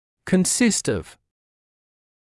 [kən’sɪst ɔv][кэн’сист ов]состоять из